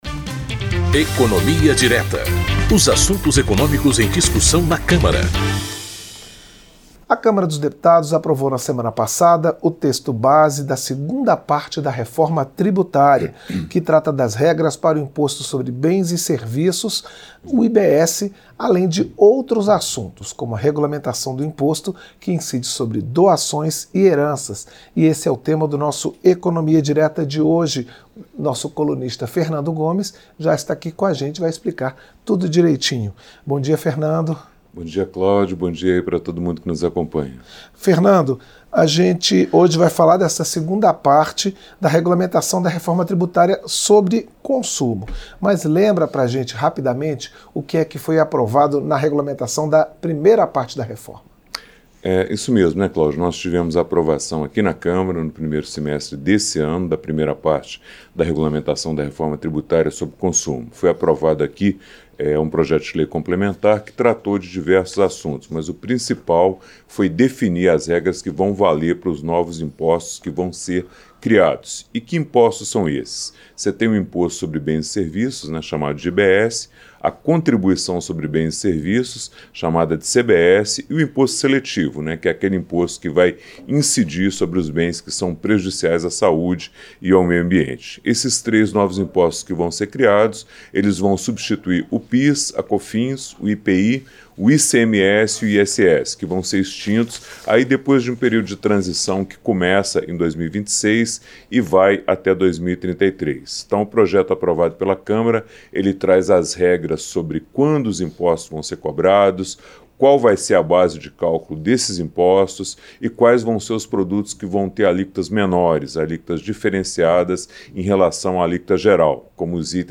Comentário
Apresentação